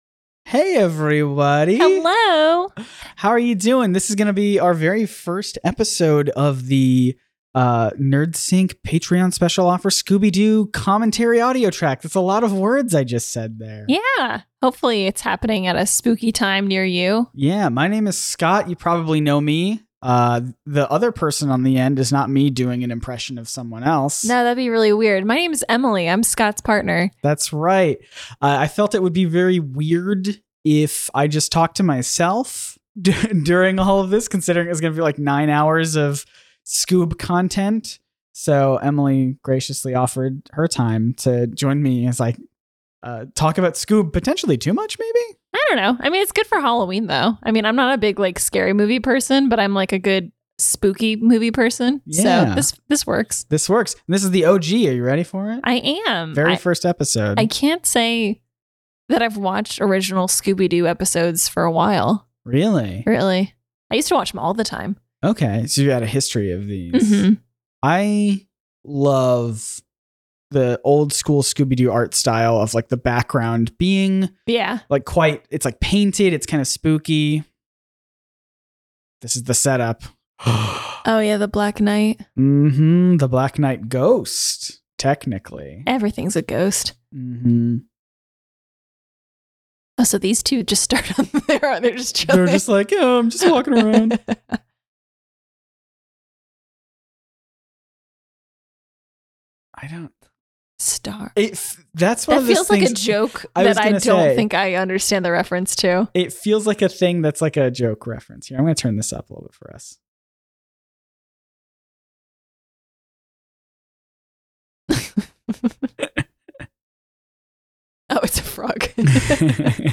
To properly sync up this episode, hit play on this audio commentary track right as the theme song vocals in the episode say the first "Scooby" after that sick opening drum fill.
Well this was just delightful, also no swears here.😄